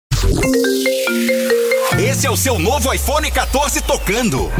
sinal-iphone-14-promocao.mp3